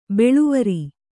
♪ beḷuvari